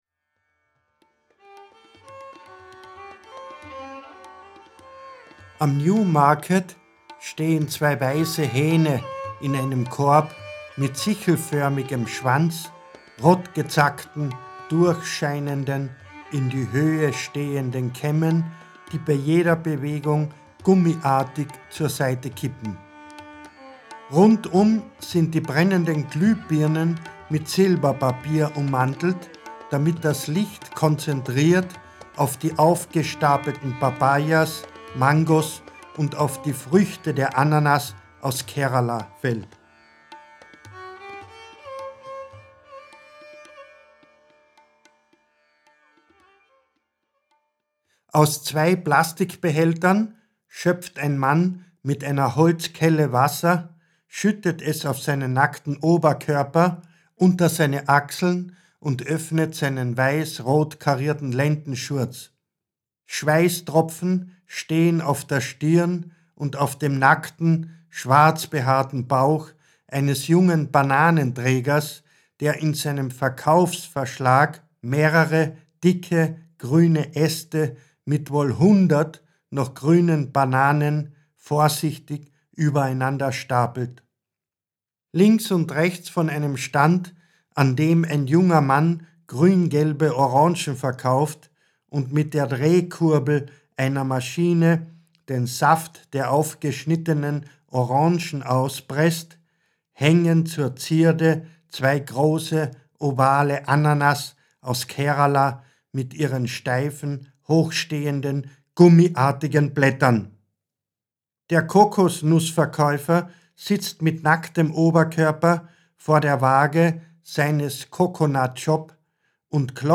Ungekürzte Autoren-Lesung
Josef Winkler (Sprecher)